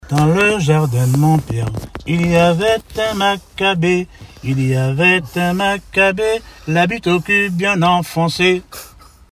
chansons de traditions orales
Pièce musicale inédite